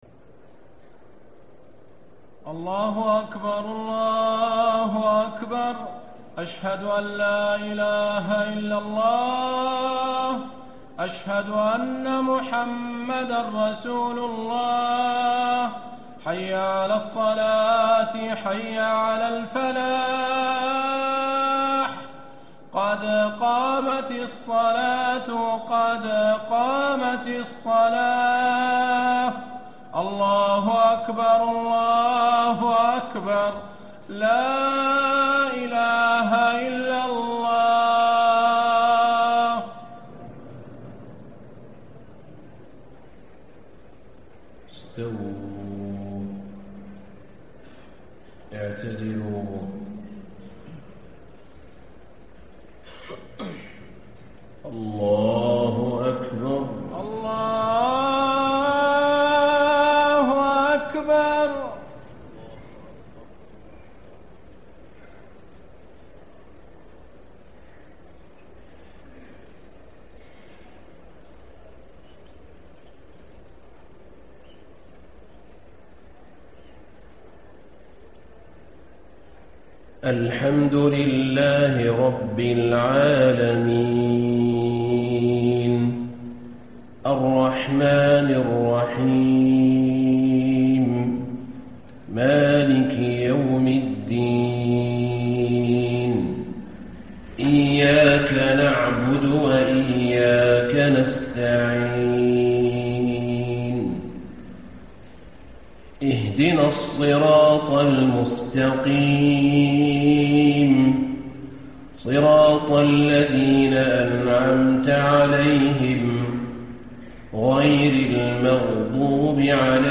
عشاء 8 شعبان ١٤٣٥ سورة البروج > 1435 🕌 > الفروض - تلاوات الحرمين